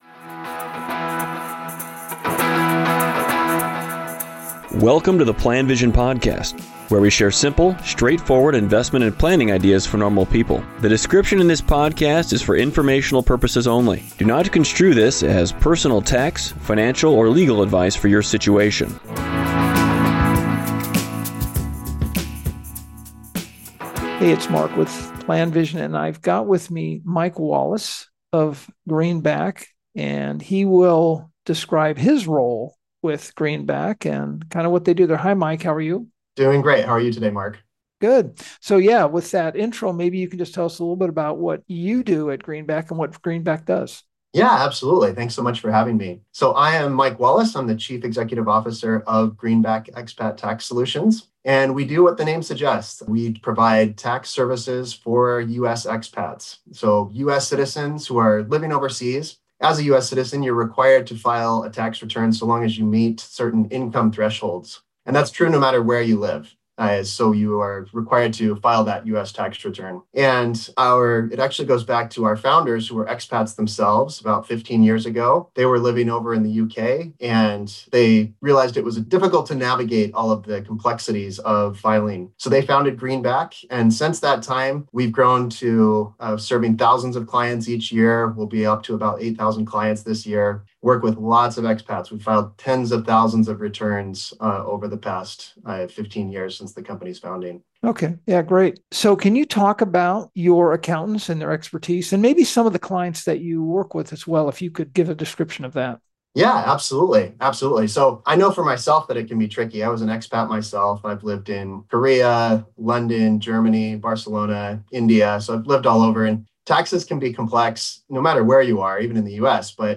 PlanVision-Podcast-2024-Greenback-Tax-Interview.mp3